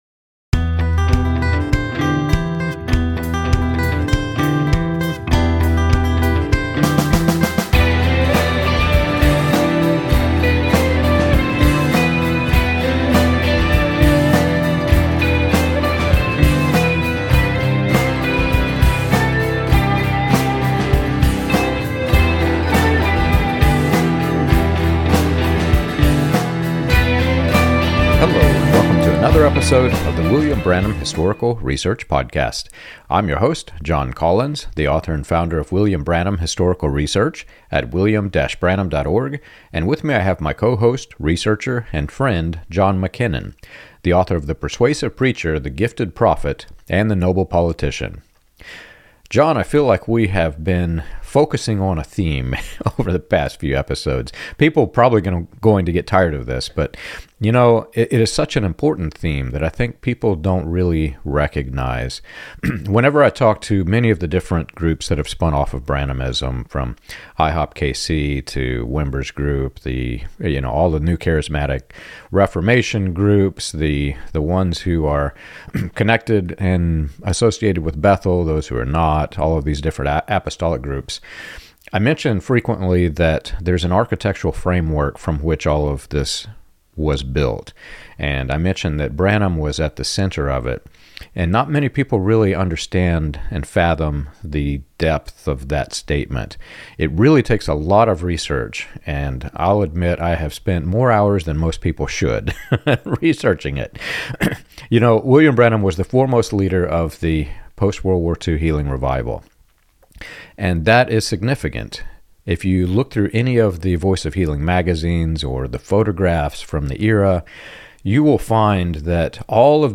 The conversation highlights how these systems subtly redefine Jesus, elevate prophetic authority, and reshape Christianity into a hierarchy of "initiated" believers while retaining the language of orthodoxy.